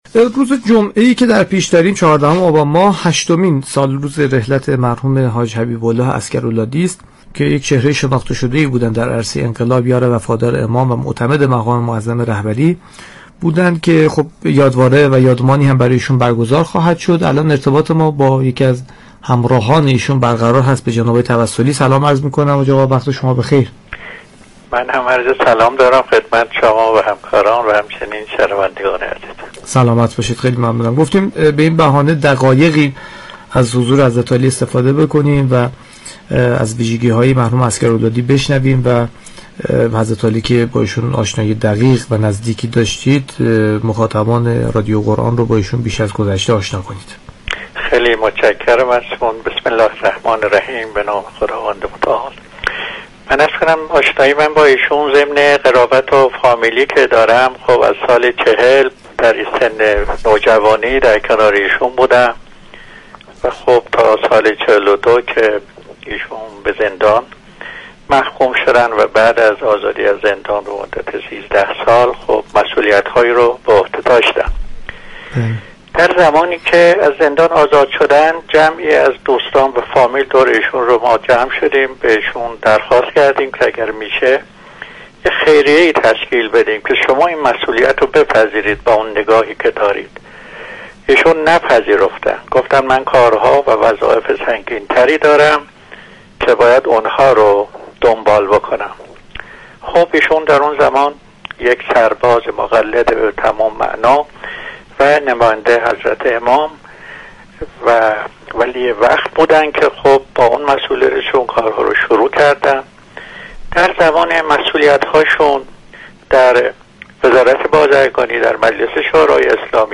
در گفتگو با برنامه والعصر